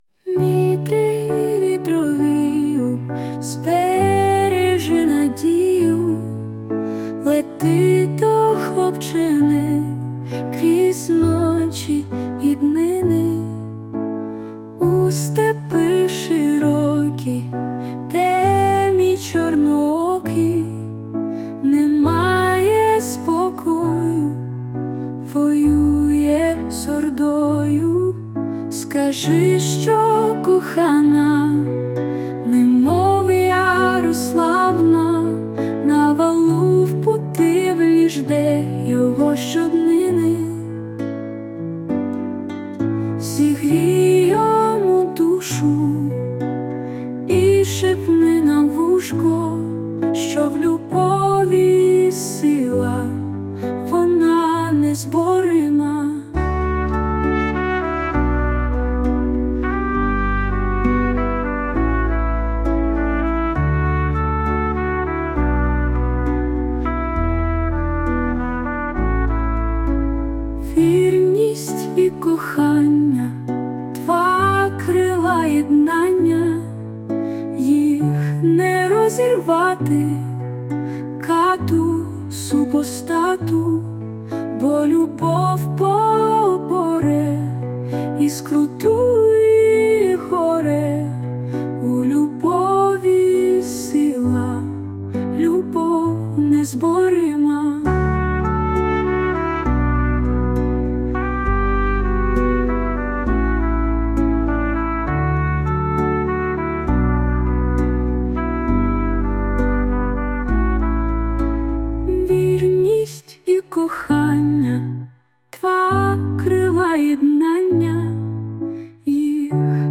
Музична композиція ШІ
ТИП: Пісня
СТИЛЬОВІ ЖАНРИ: Ліричний